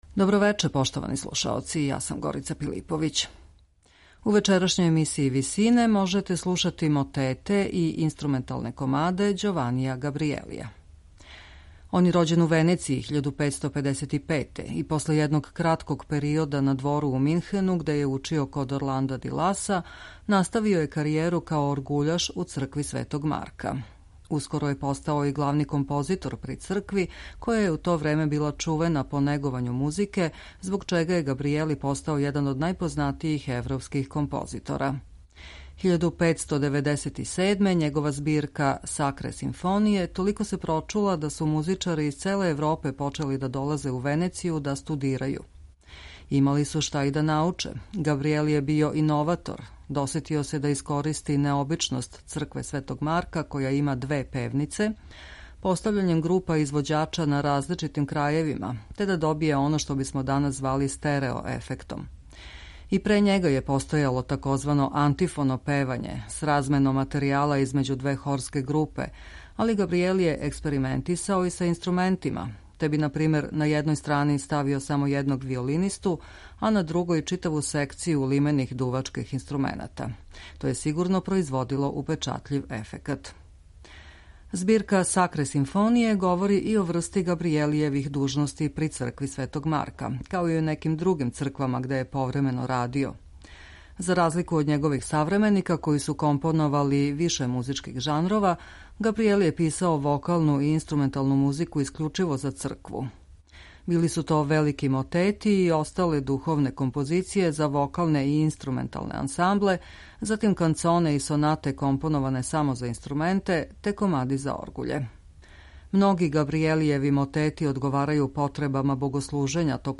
можете слушати мотете и инструменталне комаде Ђованија Габријелија.